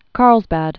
(kärlzbăd)